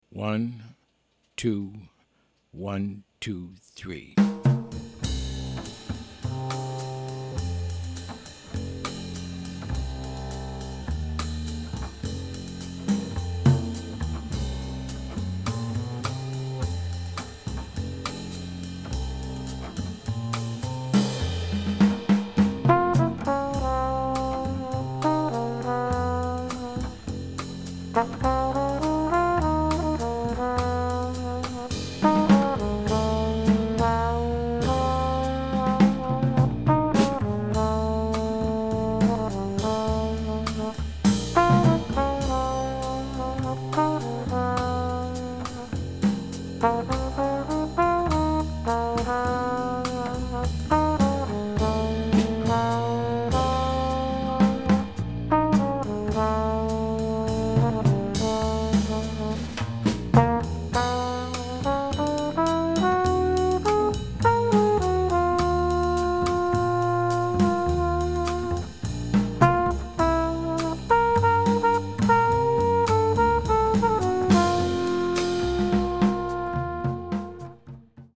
This simple bossa with a slightly mysterious vibe